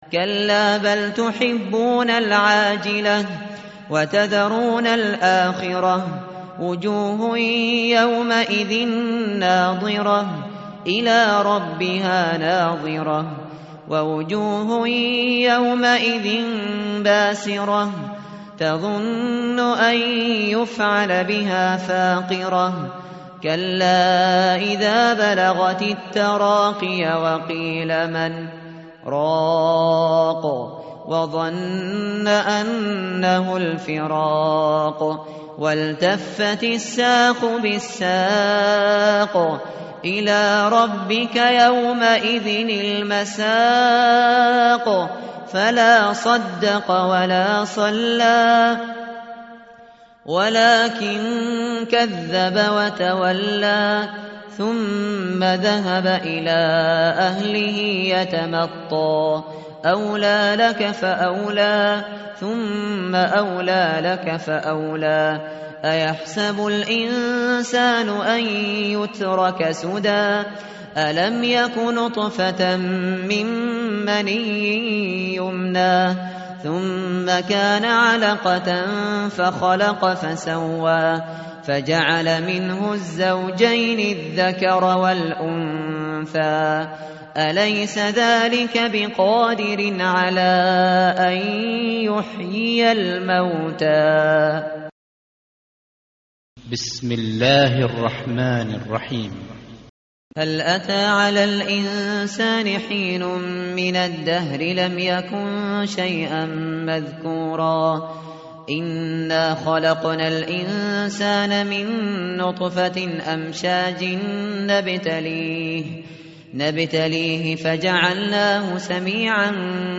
tartil_shateri_page_578.mp3